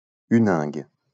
Huningue (French pronunciation: [ynɛ̃ɡ]